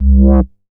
MoogFilta 006.WAV